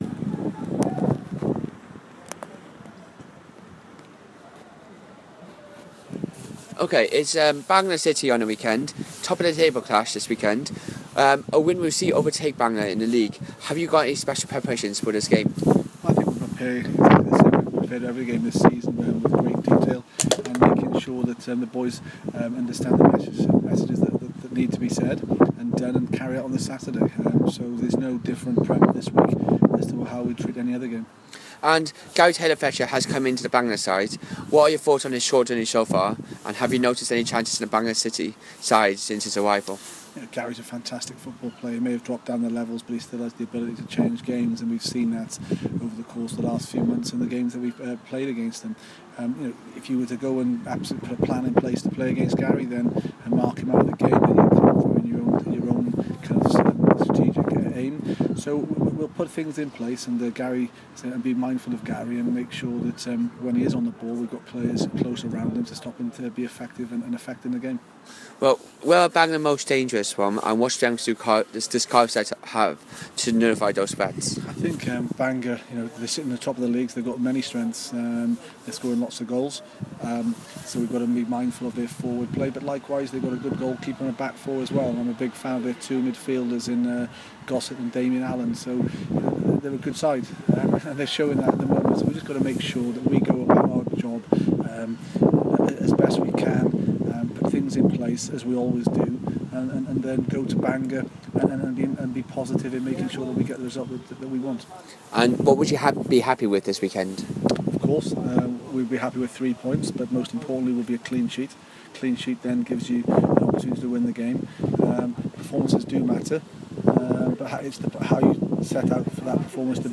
(Apologies for bad audio)